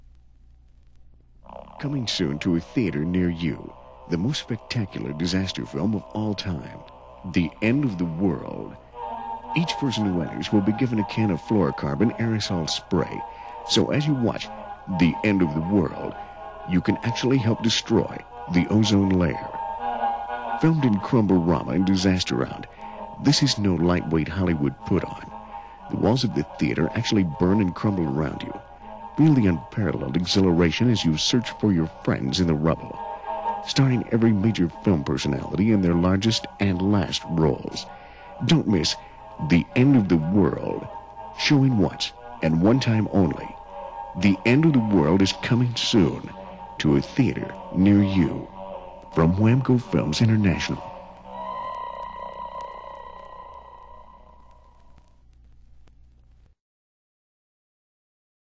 He has used the studio recording equipment and software at KONA to dub the LP tracks sans clicks and pops!.